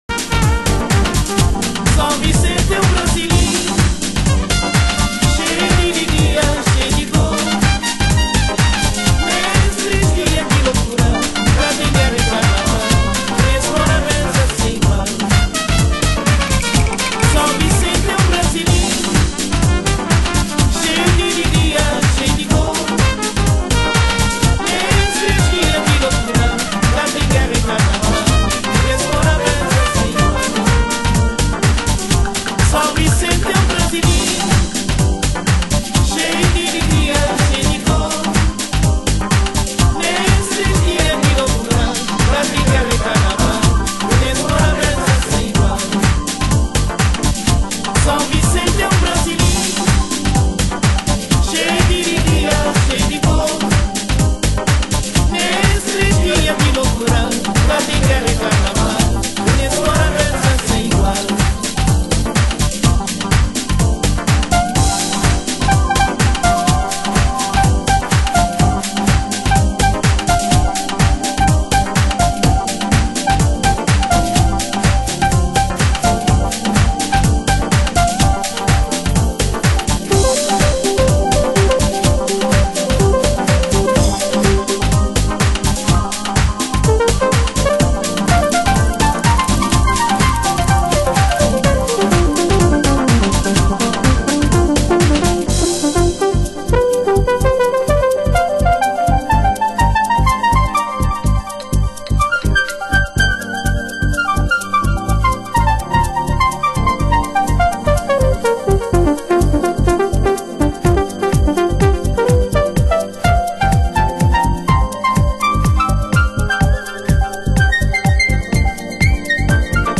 HOUSE MUSIC
盤質：小傷有/少しチリパチノイズ有　　ジャケ：少しスレ有